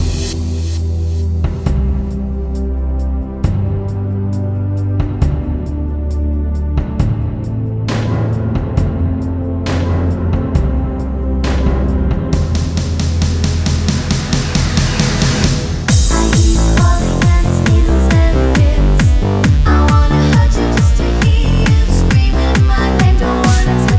no Backing Vocals Dance 3:05 Buy £1.50